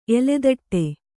♪ eledaṭṭe